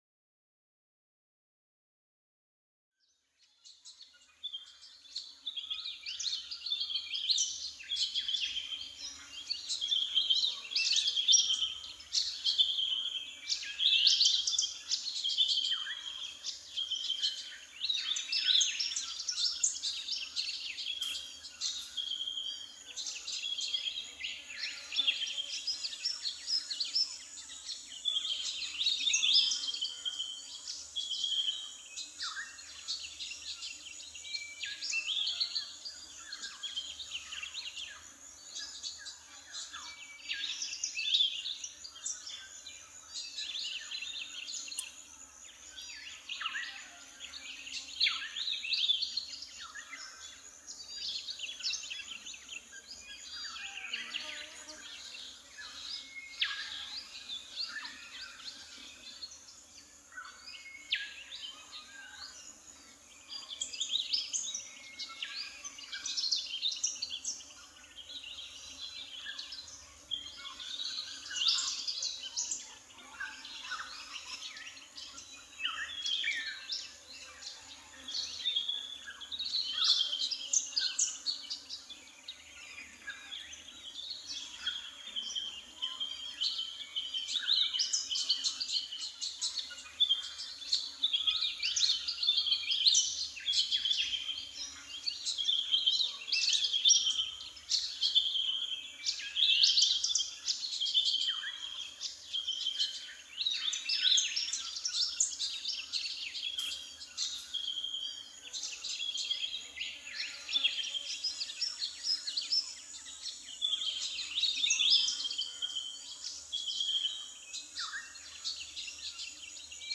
AMB_Scene03_Ambience_R.ogg